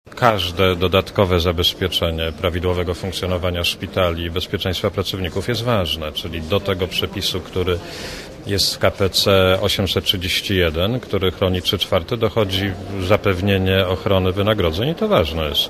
Mówi minister zdrowia Marek Balicki